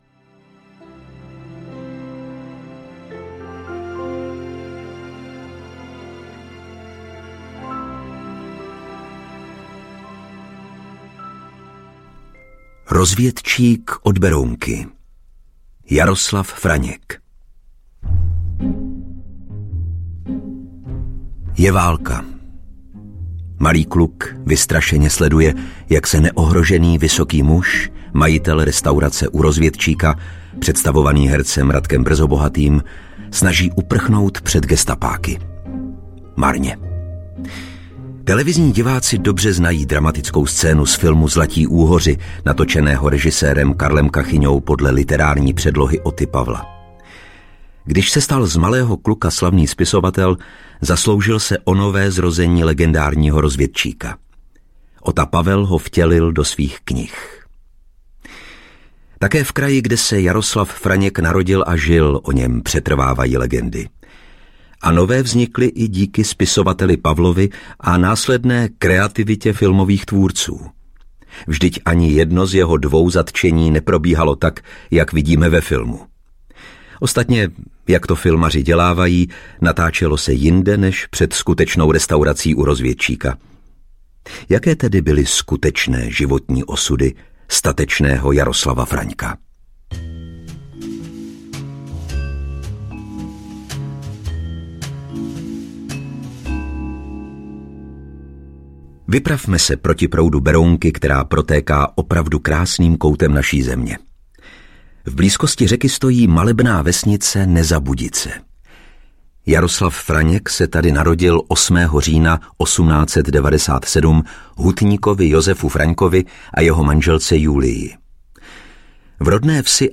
Neznámí hrdinové audiokniha
Ukázka z knihy